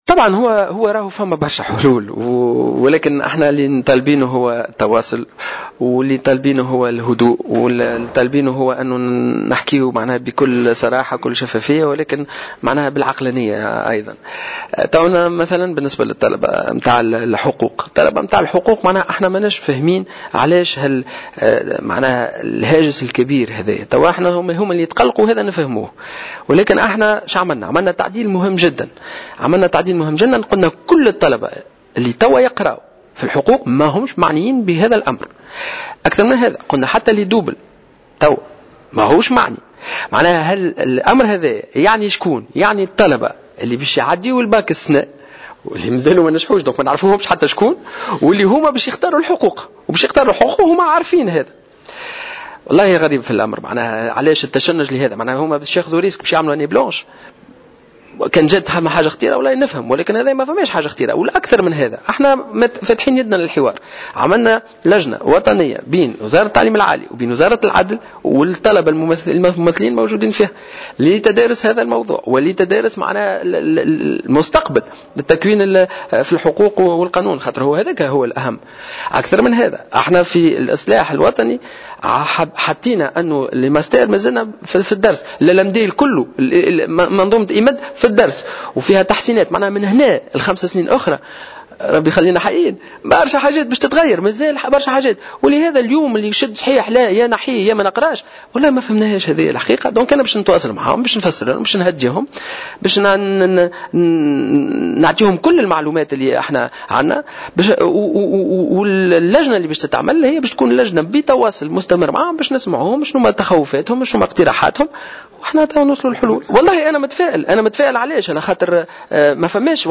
Dans une déclaration accordée au correspondant de Jawhara FM à Sfax en marge d'une manifestation scientifique, le ministre a rappelé que l'article 345 concernera les nouveaux étudiants qui seront inscrits à la première année à partir de l'année universitaire prochaine.